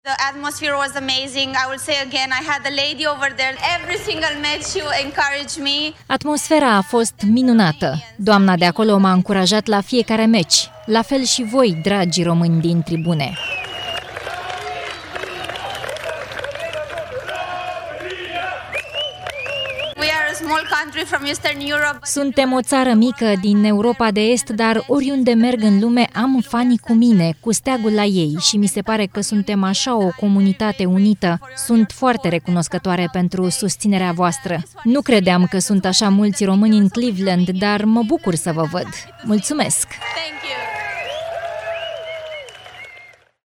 Mesaj emoționant din partea Soranei Cîrstea pentru fanii români, la ceremonia în care i-a fost înmânat trofeul turneului de la Cleveland.
25aug-15-Carstea-–-Ce-de-romani-in-Cleveland-Tradus.mp3